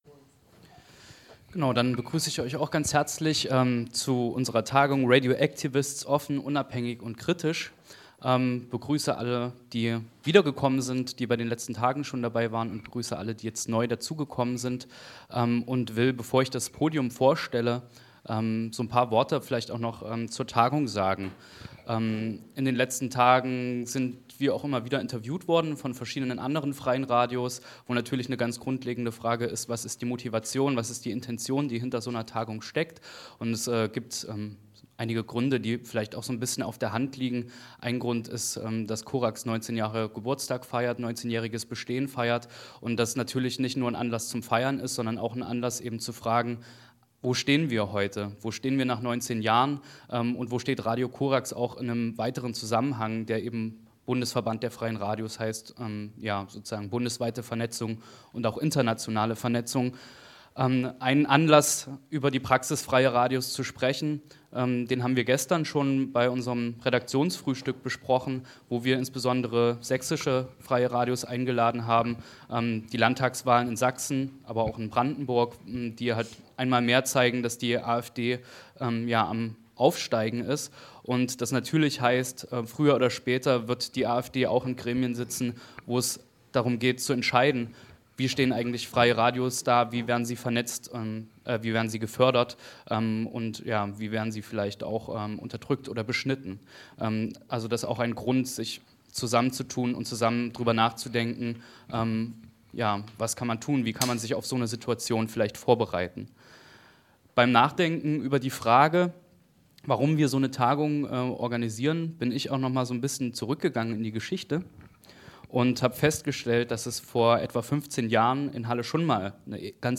sprachen im Zuge einer Tagung in Halle über die Schwierigkeiten des Anfangs und Weitermachens eben jener Radioprojekte.